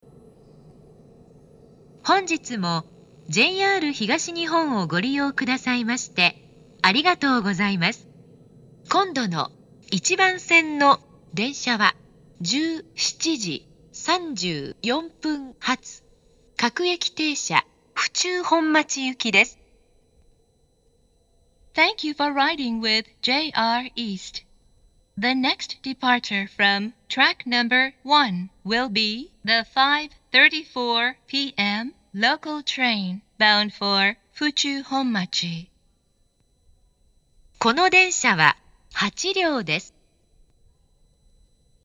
発車メロディー（ＪＲＥ-ＩＫＳＴ-０0１-0
以前よりも曲は短くなりましたが、日中は鳴りにくいです。
hatchobori1bansen-jihatu2.mp3